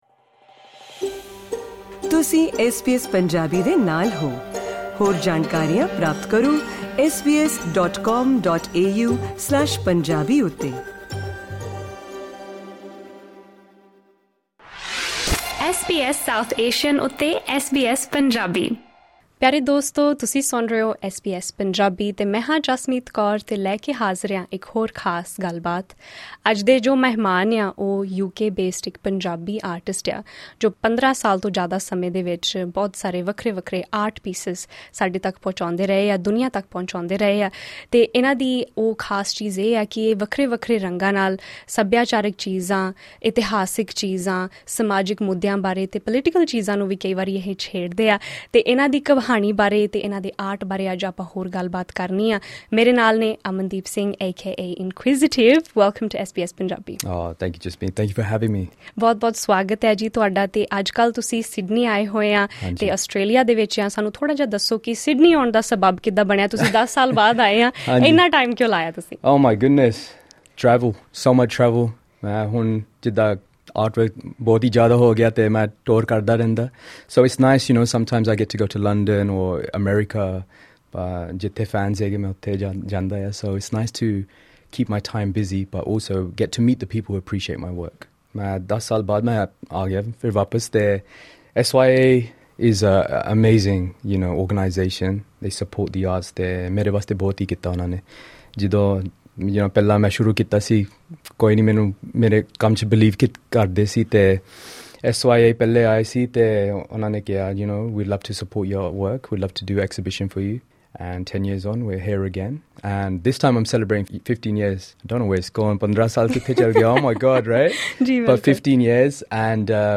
ਵਿਸ਼ੇਸ਼ ਗੱਲਬਾਤ